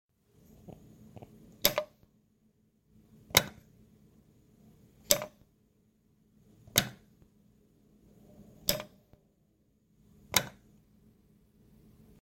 电力 " 闪烁 004
描述：从灯管中取样的闪烁严重降噪且时间短，但效果很好，特别是有一点混响
标签： 闪烁
声道立体声